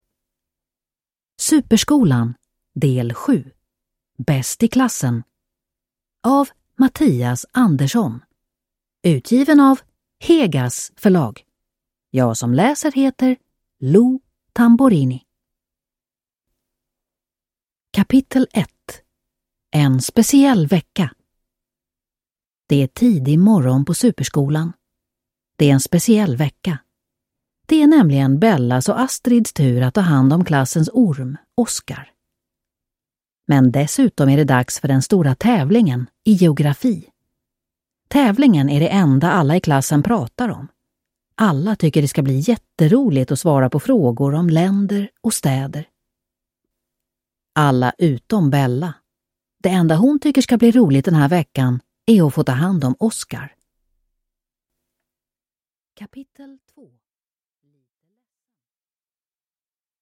Bäst i klassen – Ljudbok